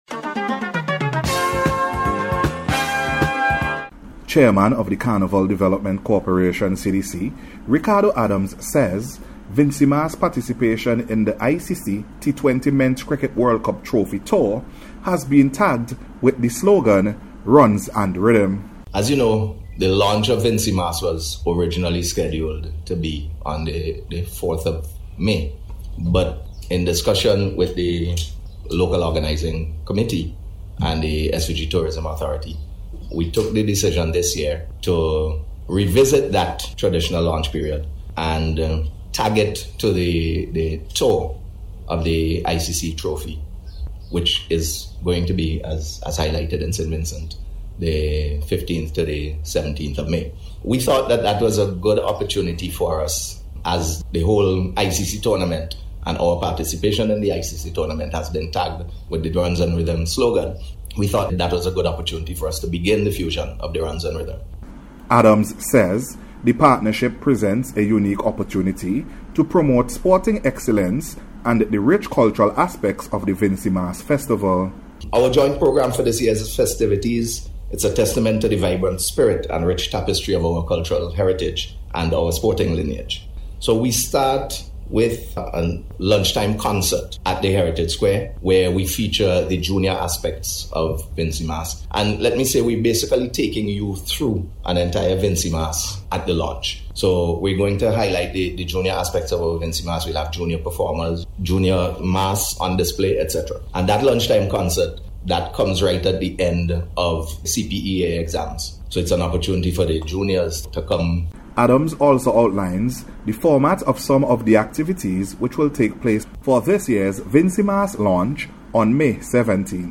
NBC’s Special Report – Monday April 29th 2024
VINCY-MAS-LAUNCH-REPORT.mp3